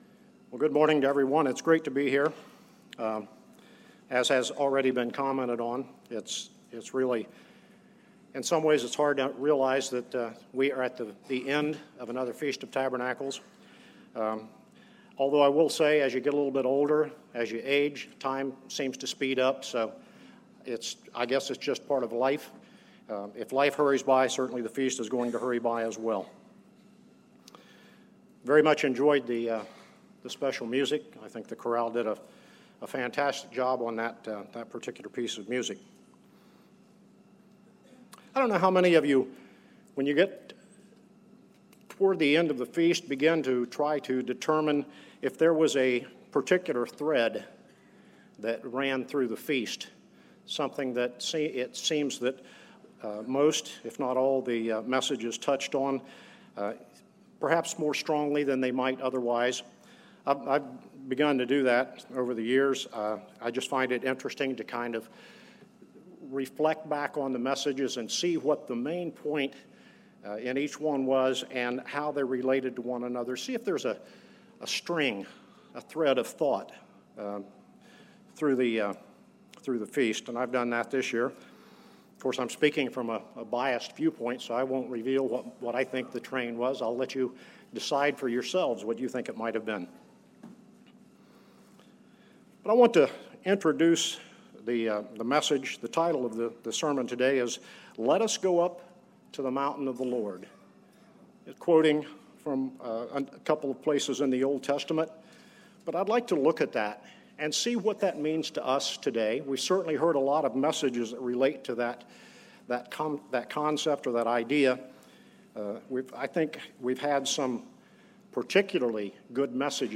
This sermon was given at the White Haven, Pennsylvania 2017 Feast site.